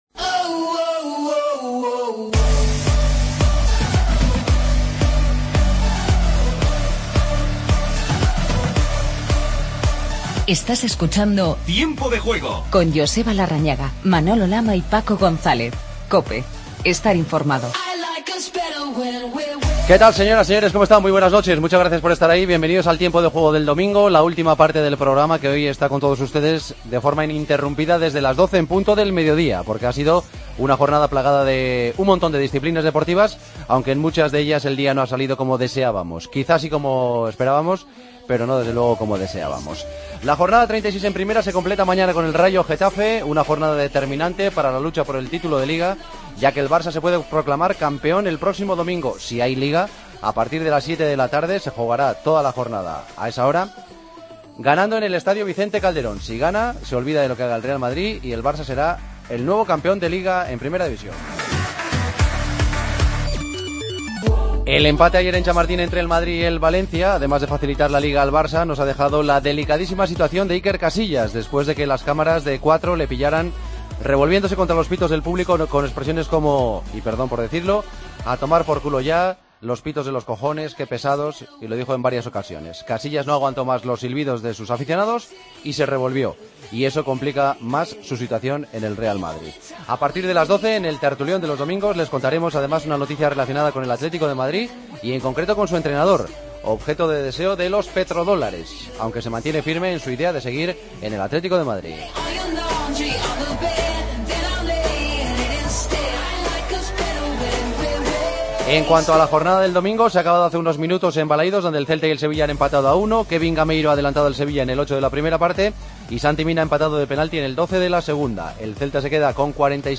Entrevista a Fernando Roig.